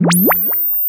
Index of /musicradar/sci-fi-samples/Theremin
Theremin_FX_11.wav